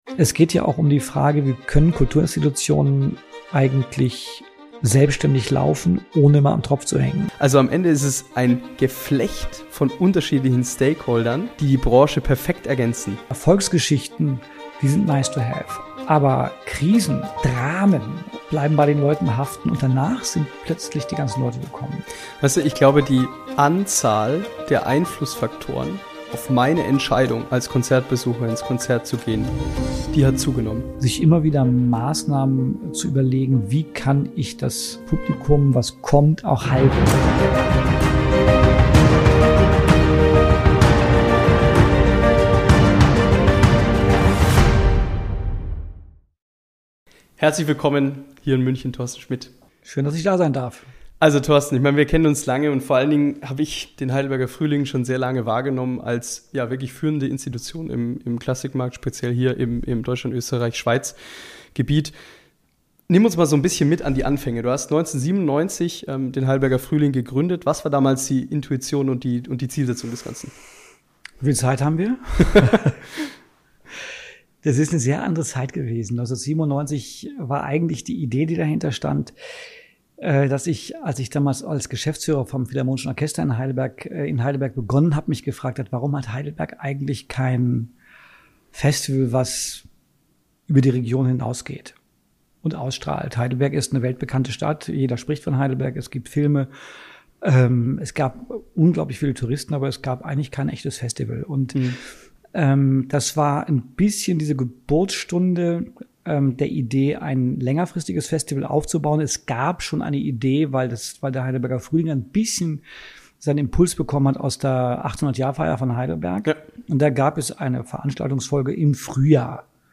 im Gespräch! ~ Business of Classical Music Podcast